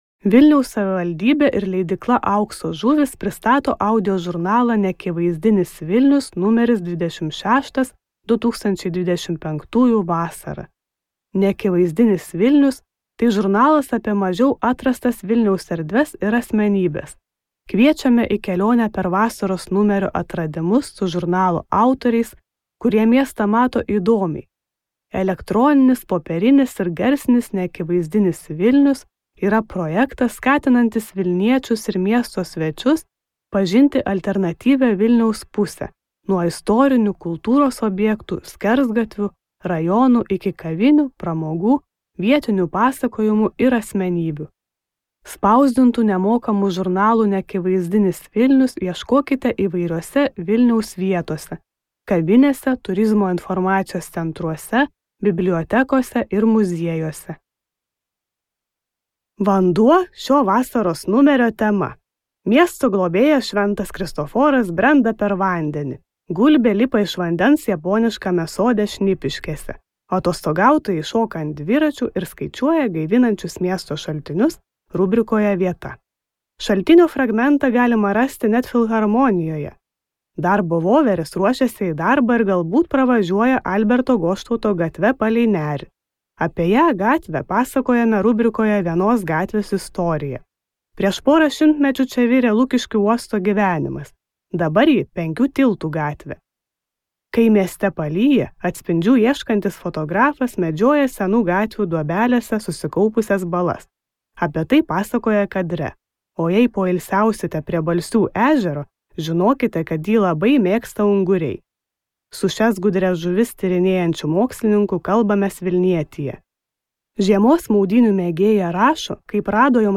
Neakivaizdinis Vilnius. 2025 vasara | Audioknygos | baltos lankos